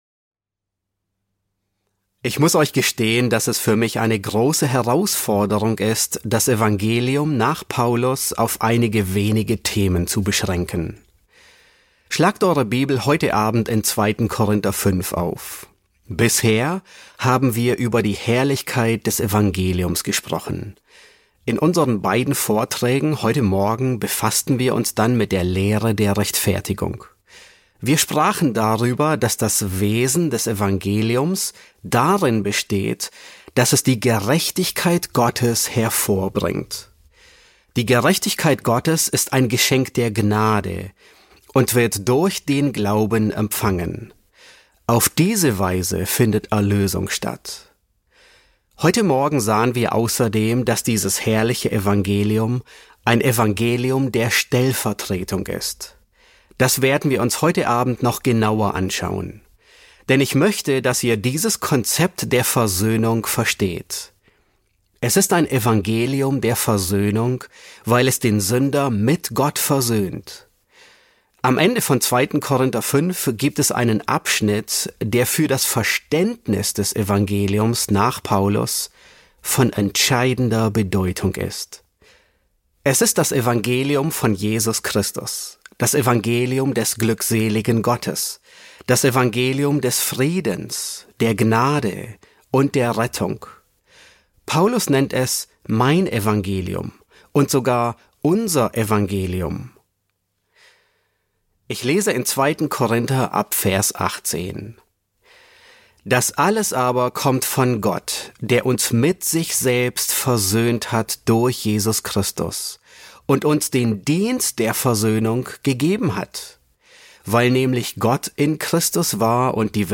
S8 F4 | Das Evangelium der Versöhnung ~ John MacArthur Predigten auf Deutsch Podcast